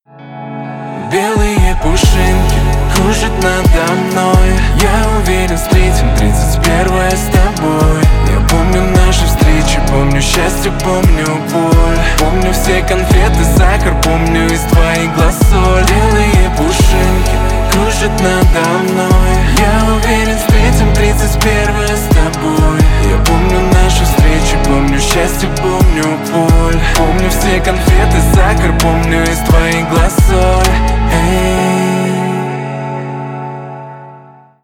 рэп
хип-хоп